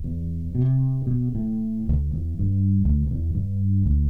BASS LOOPS - PAGE 1 2 4 5